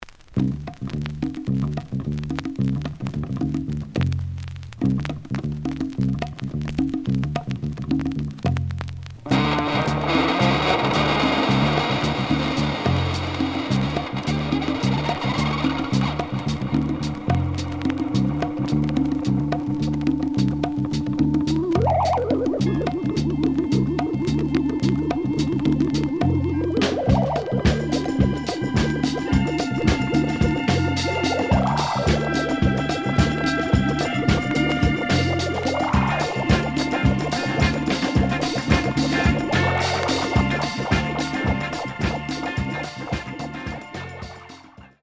The audio sample is recorded from the actual item.
●Genre: Funk, 70's Funk
●Record Grading: VG- (傷は多いが、B面のプレイはまずまず。B side plays good.)